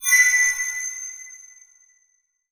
magic_shinny_high_tone_03.wav